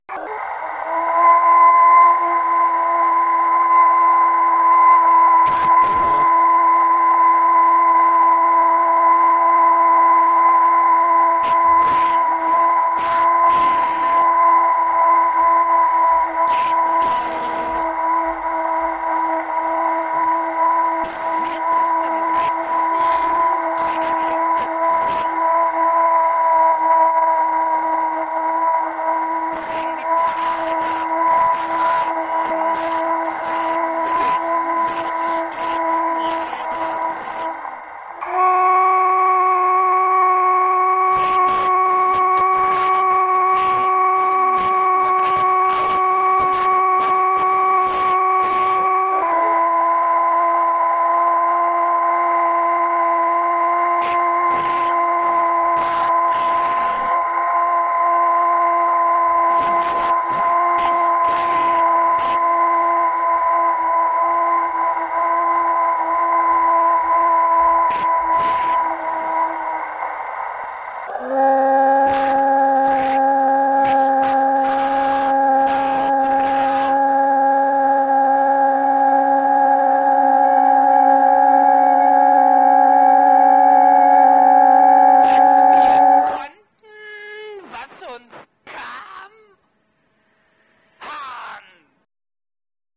Ten miniatures of FULL BLADDER POETRY.